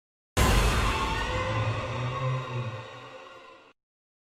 monster_growl.ogg